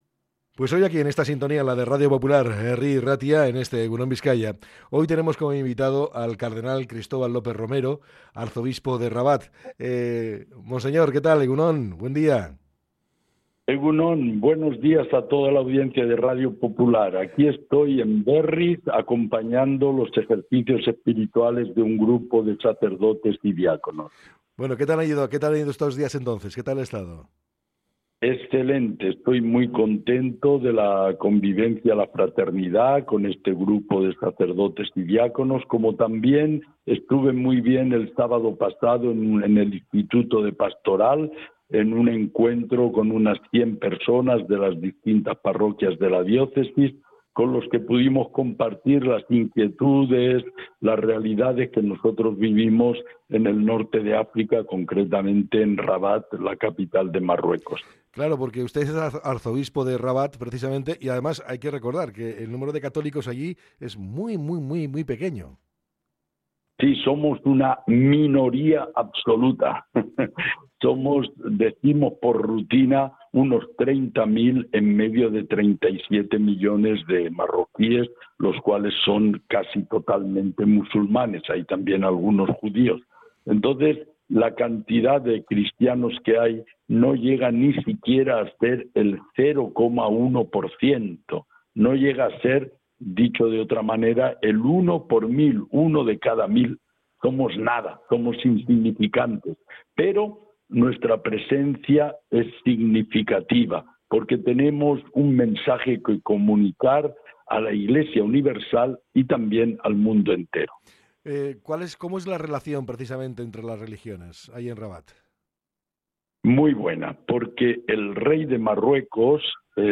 El arzobispo de Rabat ha analizado también la tragedia de los jóvenes migrantes. Escucha el podcast Cristóbal López Romero: Convivencia y esperanza desde Marruecos Cristóbal López Romero, arzobispo de Rabat, en Radio Popular.